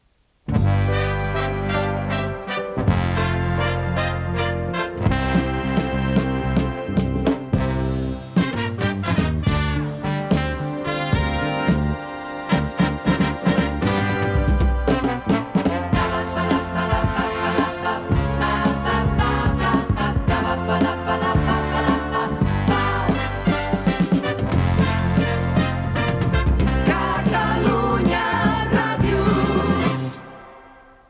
Sintonia